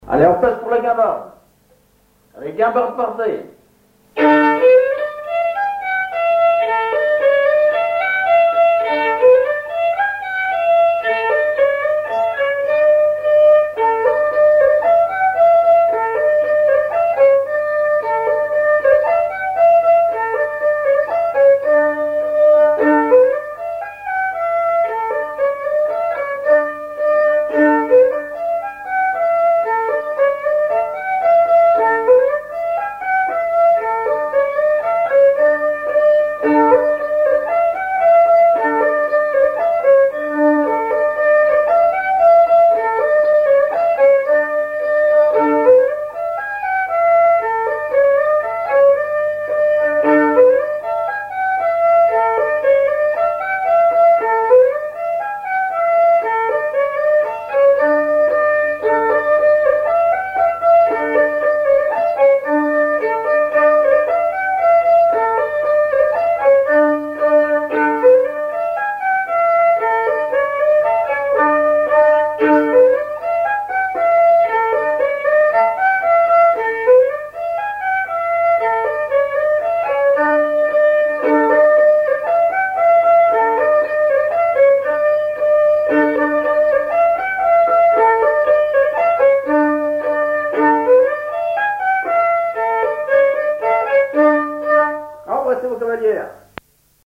Mémoires et Patrimoines vivants - RaddO est une base de données d'archives iconographiques et sonores.
Chants brefs - A danser
Auto-enregistrement
Pièce musicale inédite